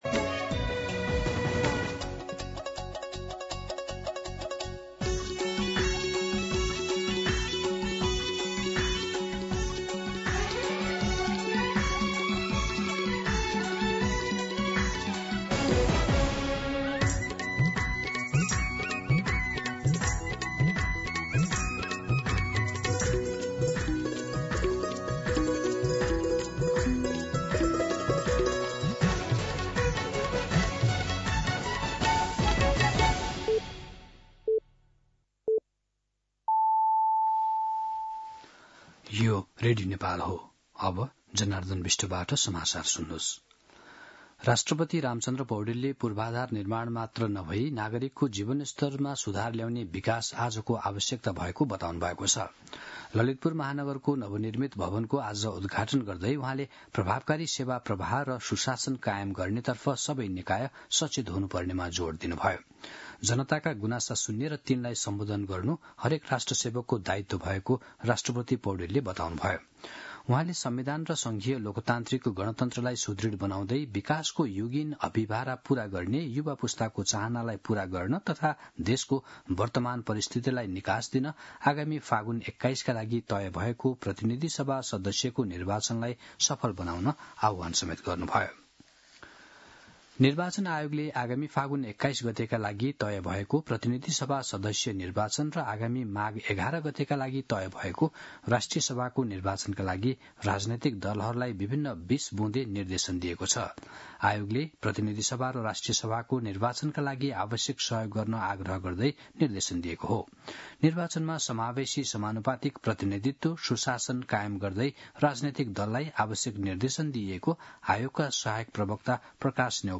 दिउँसो १ बजेको नेपाली समाचार : २९ जेठ , २०८२
1-pm-Nepali-News.mp3